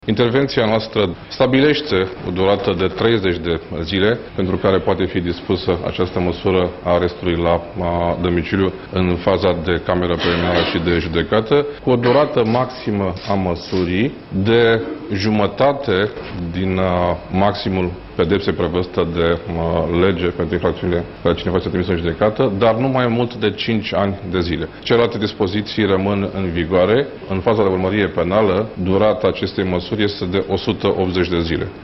Durata maximă a măsurii nu poate depăşi însă 180 de zile – a precizat ministrul Justiției, Robert Cazanciuc.